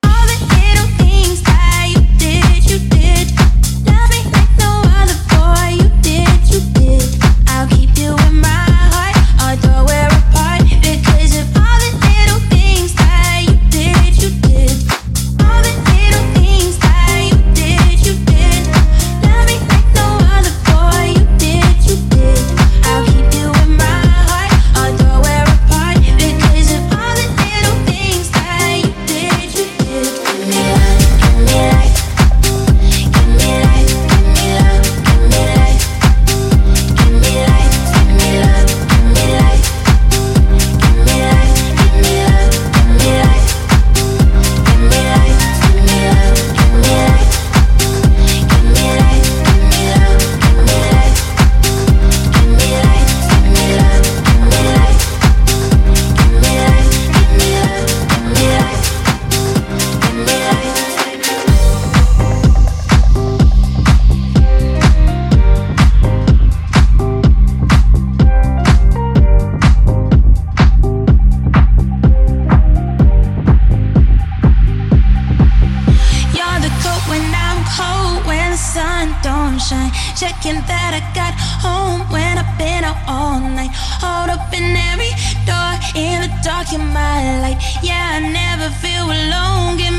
ジャンル(スタイル) HOUSE / TECH HOUSE